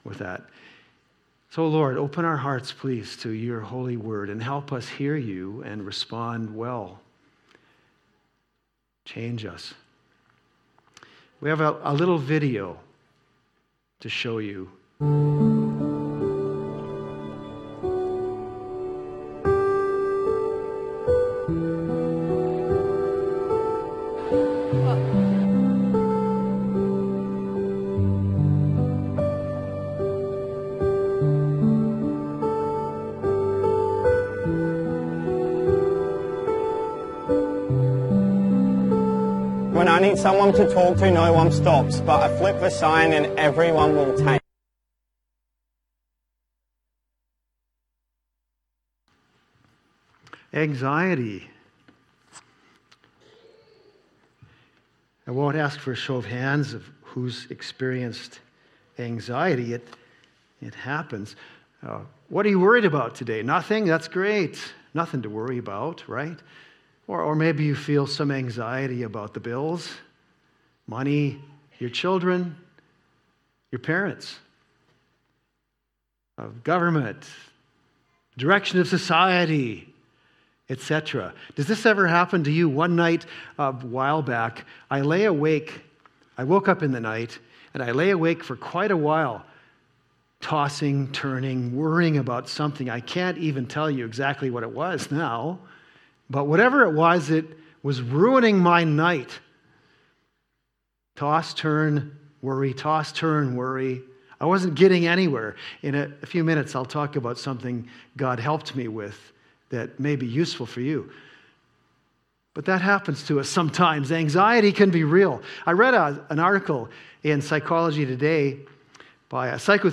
The answer to anxiety is prayer. Teaching text is from Philippians 4:6-7.